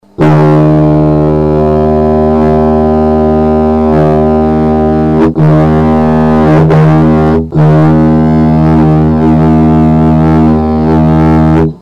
Диджериду (ольха)
Диджериду (ольха) Тональность: D#
Обладает мощным объемным звучанием и имеет хорошую отзывчивость.